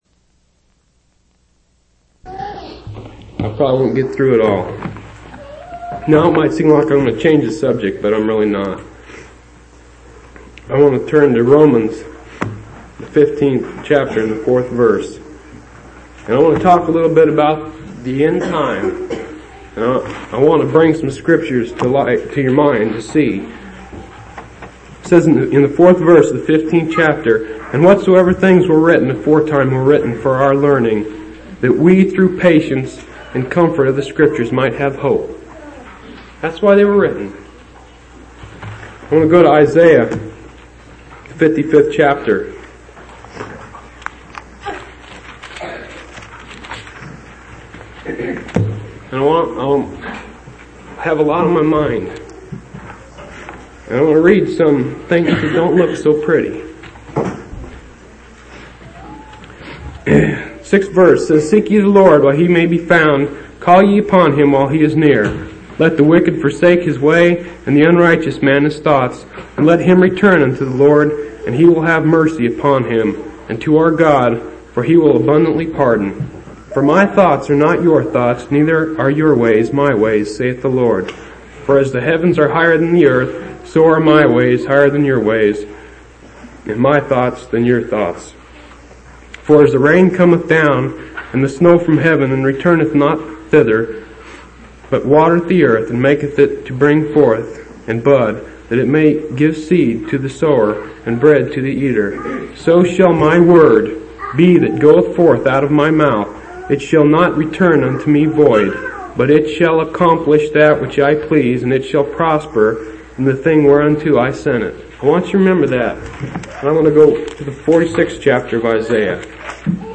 12/16/1984 Location: Phoenix Local Event